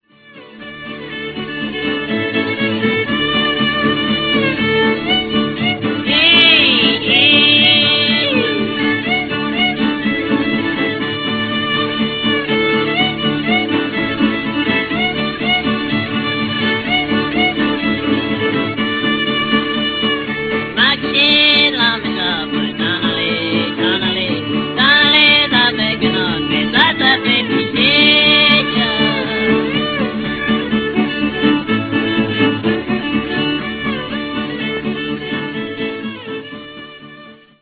fiddler
the first Cajun band to use amplification
could easily knock out a French tune when required